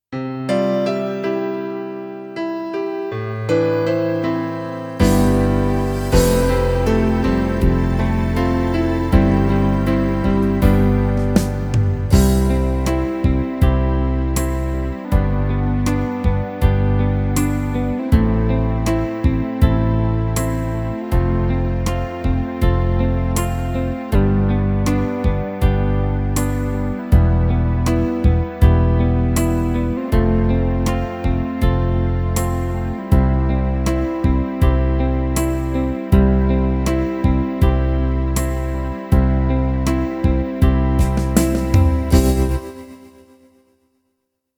The minus one track to play along with
I'd like to suggest you again, when you manage to play the solo well, without mistakes, you might try to improvise above the backing track, looking for interesting phrases and ideas.
022pianoBallad80beat.mp3